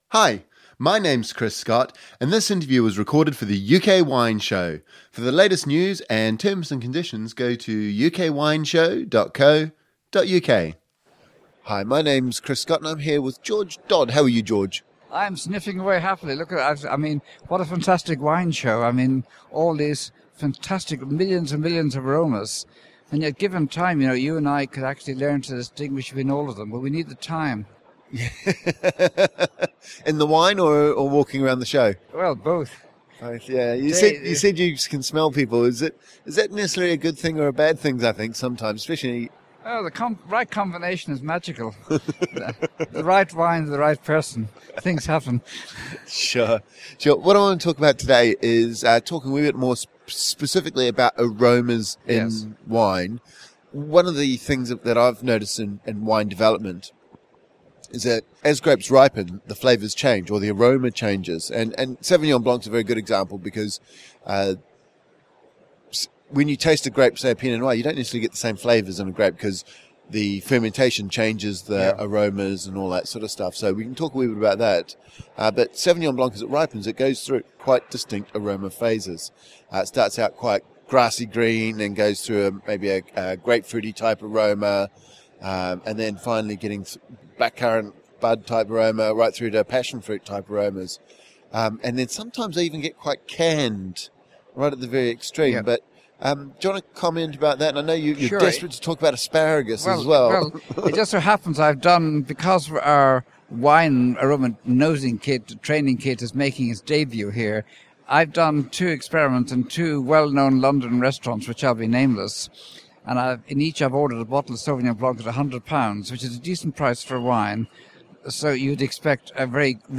During the interview we also discuss the different aromas found in wines, pheromones and how Alzheimers affects the ability to smell.